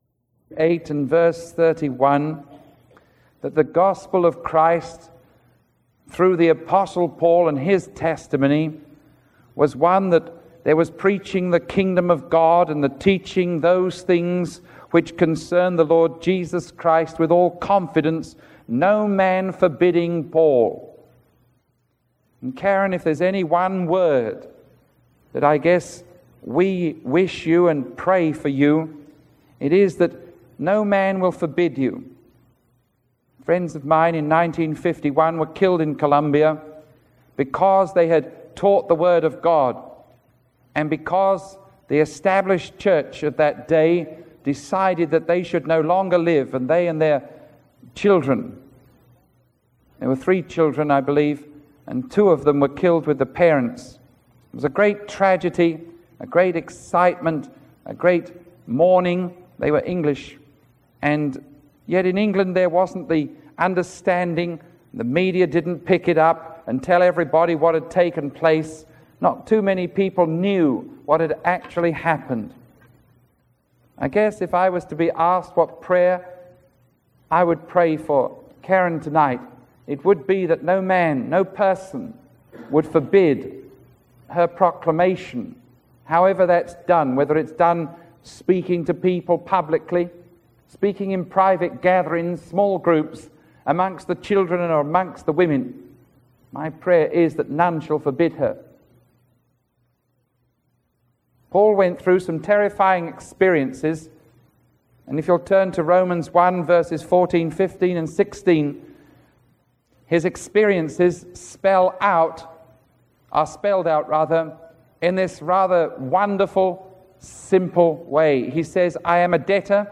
Sermon 0918A recorded on July 29